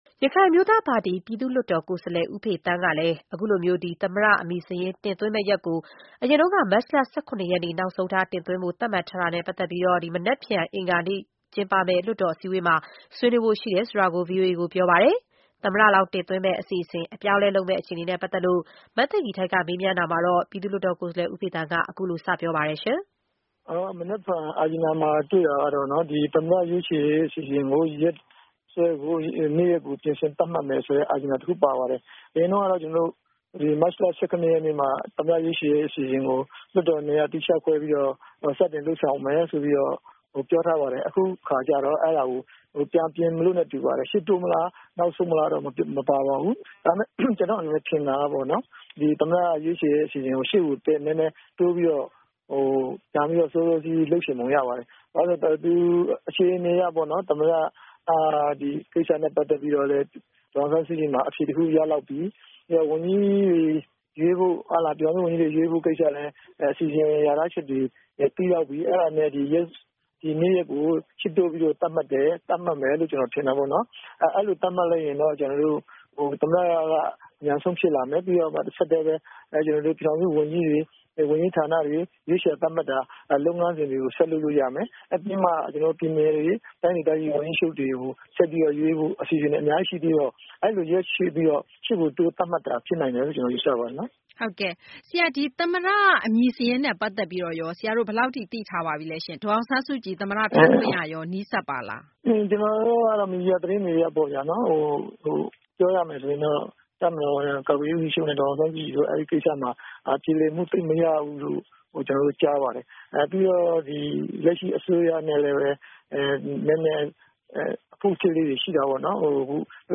ရခိုင်အမျိုးသားပါတီ ပြည်သူ့လွှတ်တော်ကိုယ်စားလှယ် ဦးဖေသန်းကို ဆက်သွယ်မေးမြန်းချက်